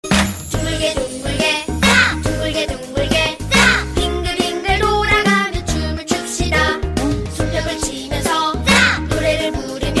Korean Nursery Rhyme